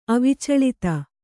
♪ avicaḷita